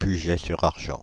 Puget-sur-Argens (French pronunciation: [pyʒɛ syʁ aʁʒɑ̃(s)]
Fr-Paris--Puget-sur-Argens.ogg.mp3